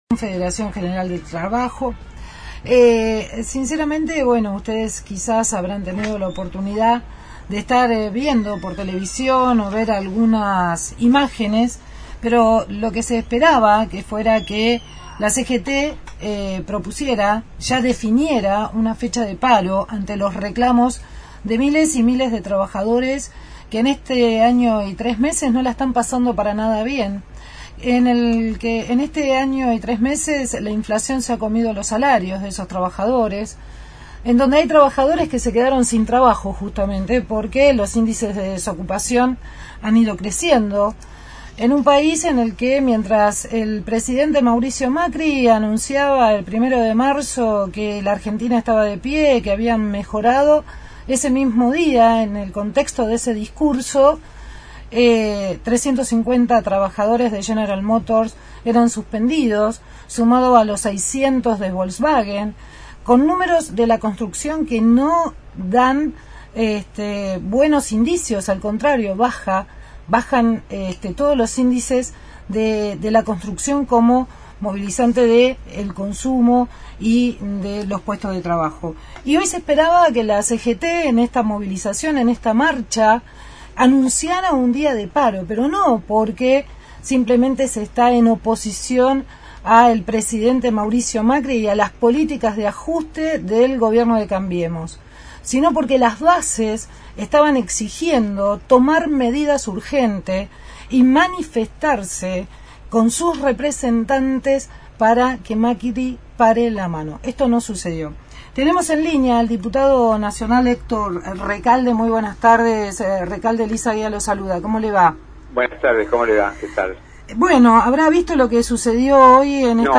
Héctor Recalde, diputado nacional por el Frente Para la Vivtoria, dialogó con el equipo de «Darás que hablar» luego de la marcha de la CGT y del discurso de los dirigentes, que no definieron fecha para el paro general.